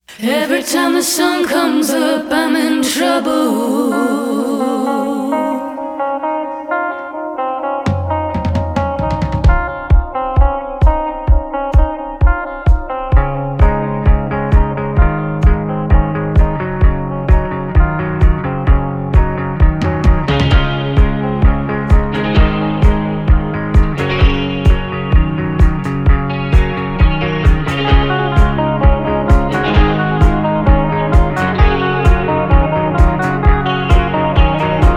Нестандартные аккорды и неожиданные переходы трека
Жанр: Альтернатива